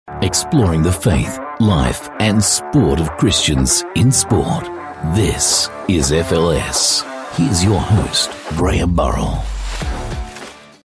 Doubleur anglais (australien)
Imagerie radio